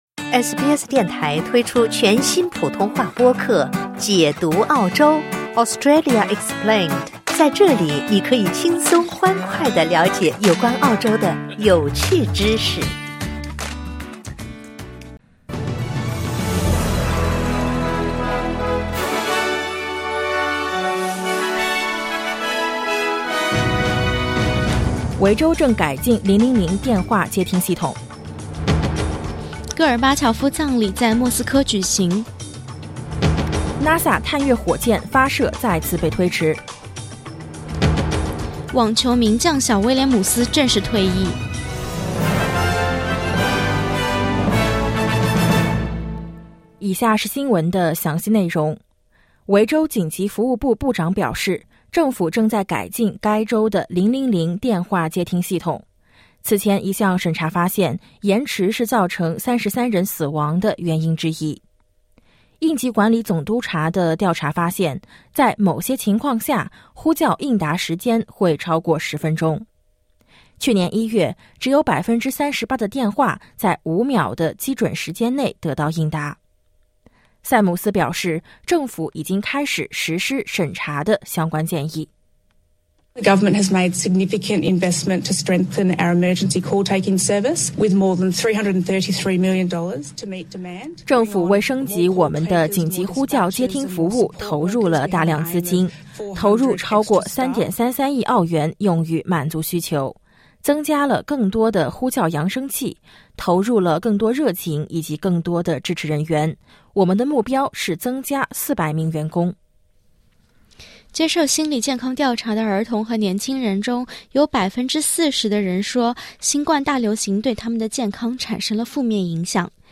SBS早新闻（2022年9月4日）